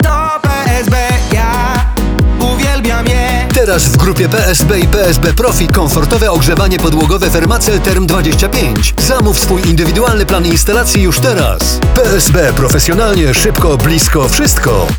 • 15-sekundowe spoty produktowe, emitowane na antenach stacji: RMF FM, RMF Maxxx, RMF Classic, Radio ZET, Antyradio, Meloradio, Chilli Zet, VOX FM oraz w rozgłośniach lokalnych.